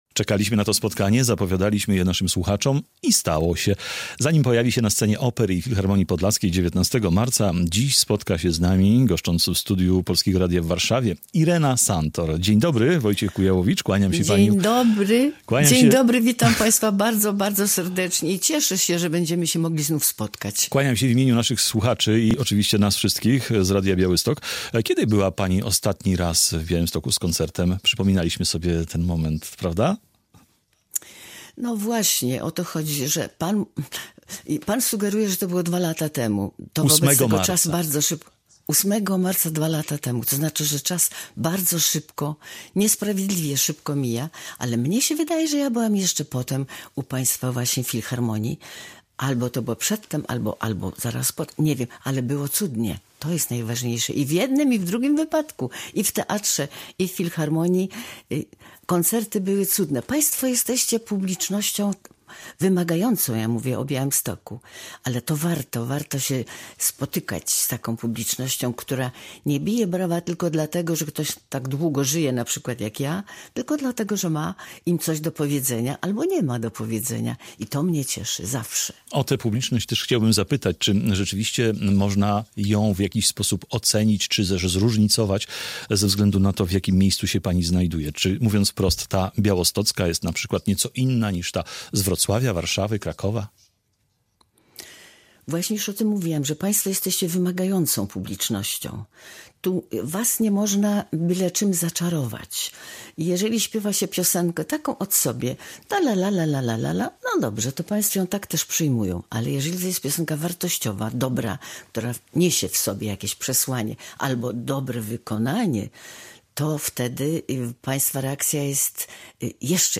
Radio Białystok | Gość | Irena Santor - piosenkarka